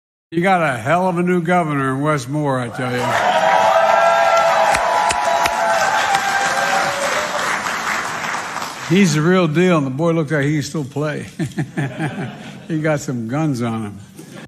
00_Biden-Speech_unknown.mp3